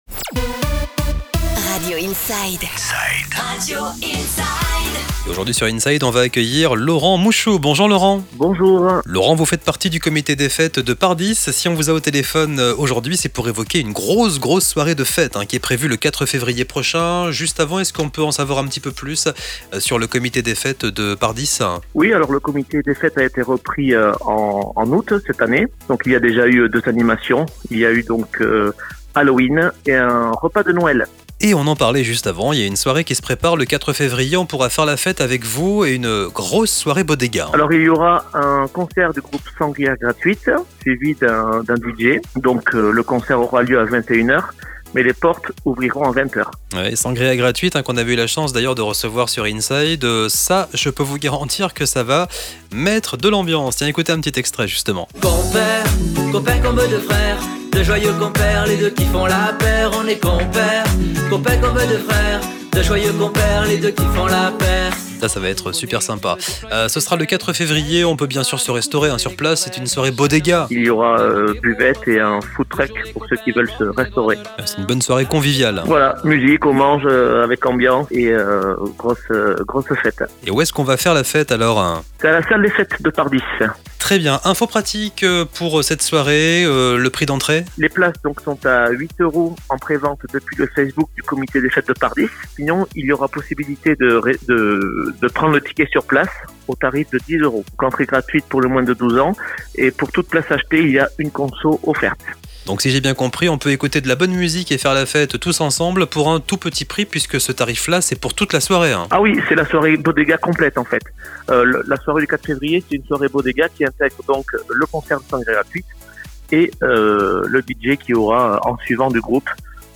INTERVIEW COMITÉ DES FÊTES DE PARDIES